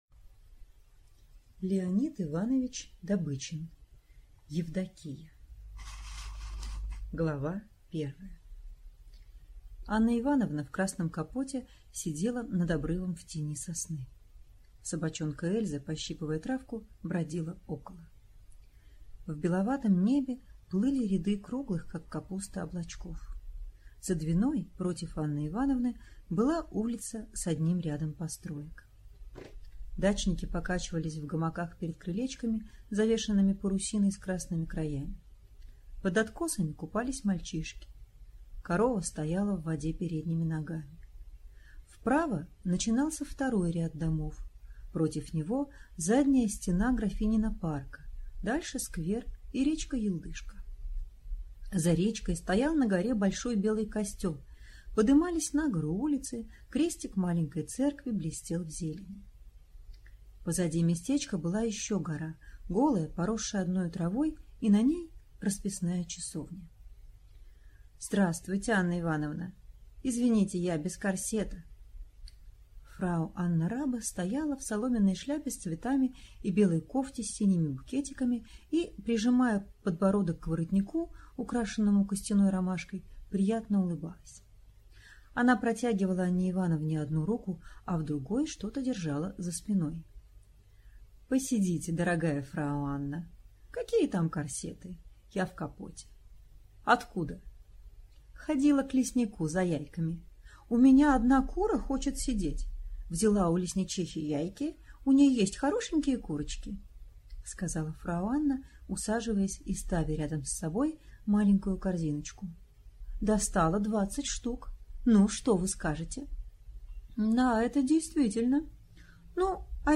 Аудиокнига Евдокия | Библиотека аудиокниг